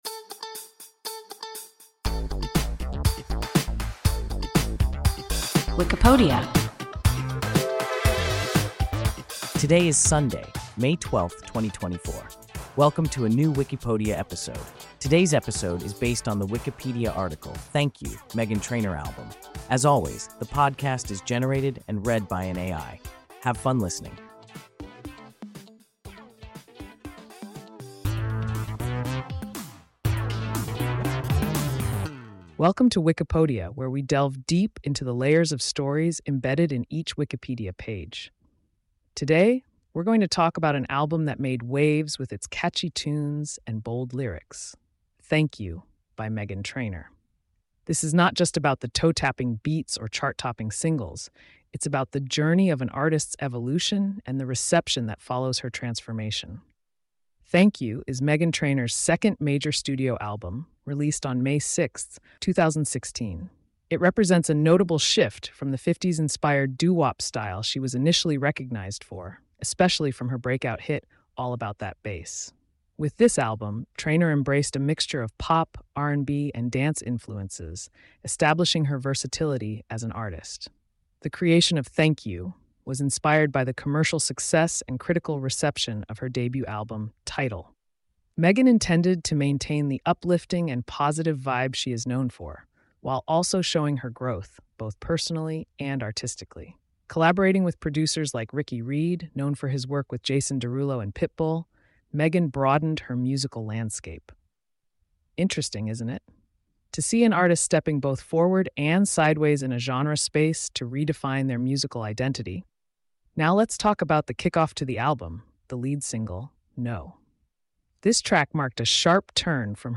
Thank You (Meghan Trainor album) – WIKIPODIA – ein KI Podcast